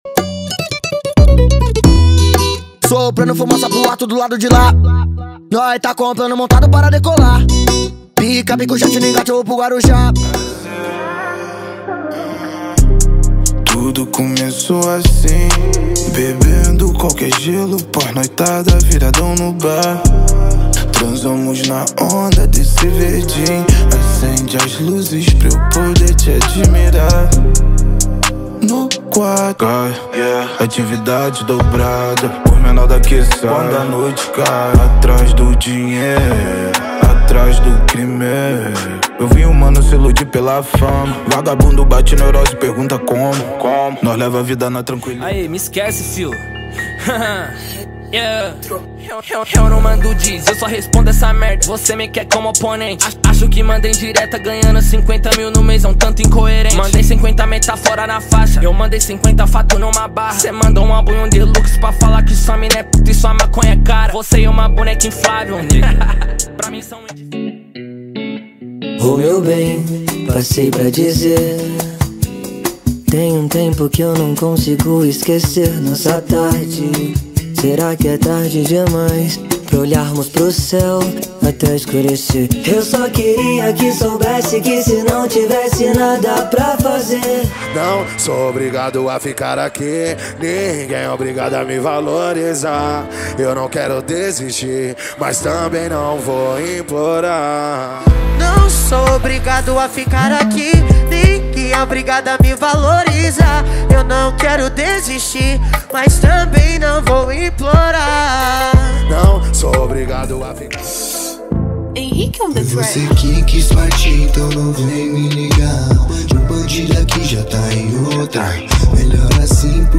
• Rap, Trap Nacional e Funk Ostentação = 50 Músicas
• Sem Vinhetas